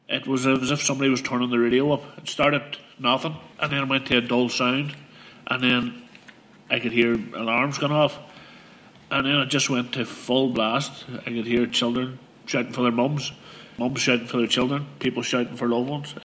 Man describes moment Omagh bomb detonated as inquiry continues